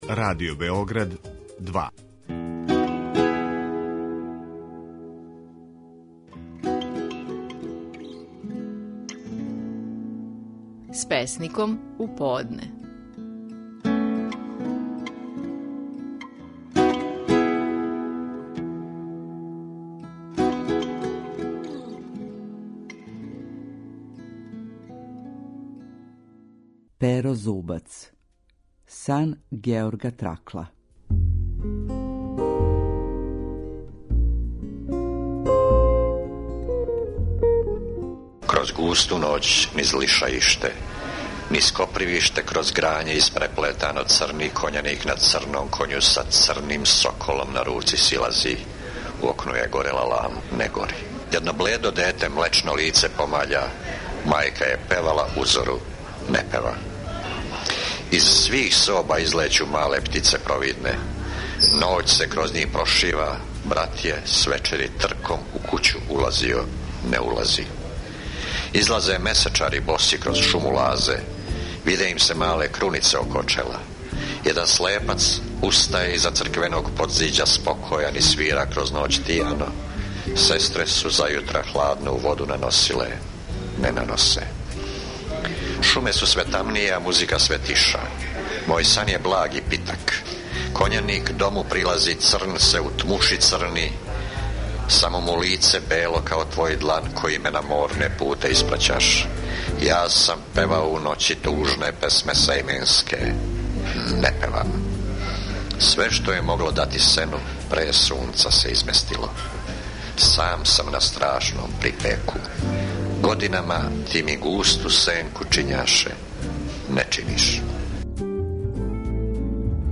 Наши најпознатији песници говоре своје стихове.
У данашњој емисији слушамо како стихове своје песме "Сан Георга Тракла" говори Перо Зубац.